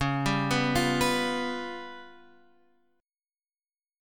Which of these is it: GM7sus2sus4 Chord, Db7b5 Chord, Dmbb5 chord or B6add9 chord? Db7b5 Chord